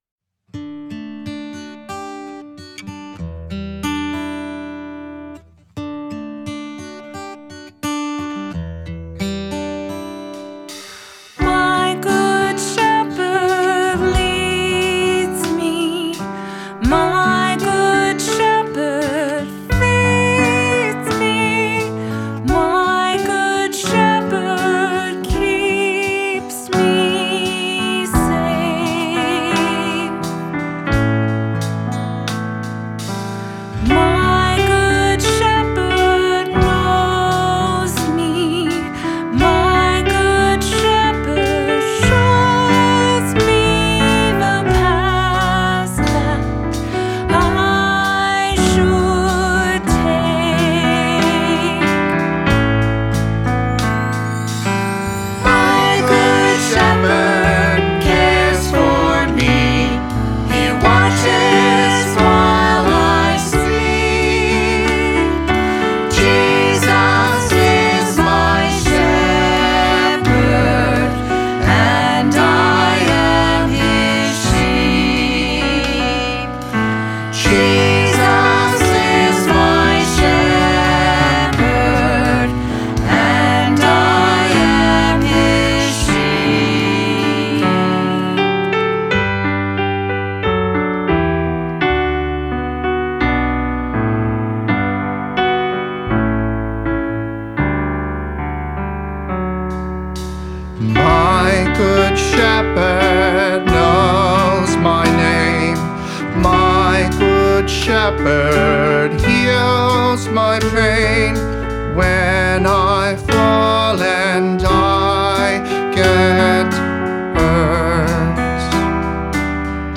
vocals, guitar, banjo, harmonica
upright bass
percussion
piano, trumpet, flugelhorn